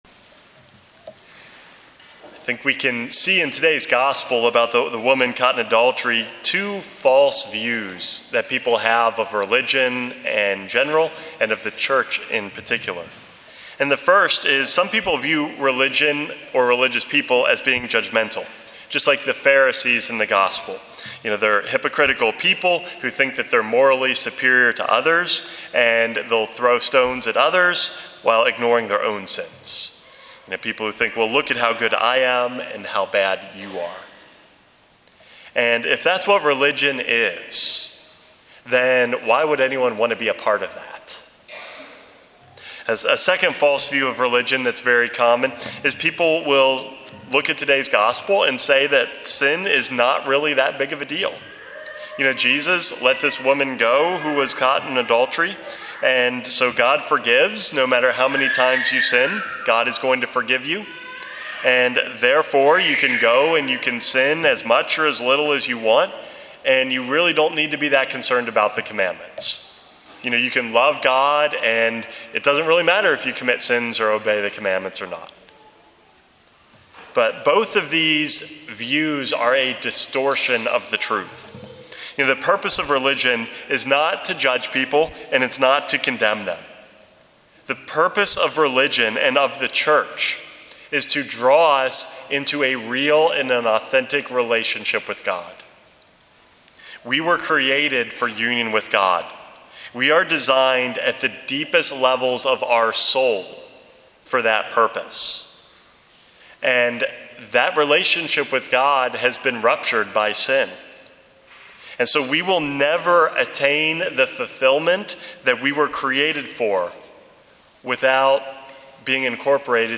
Homily #443 - Correcting the Record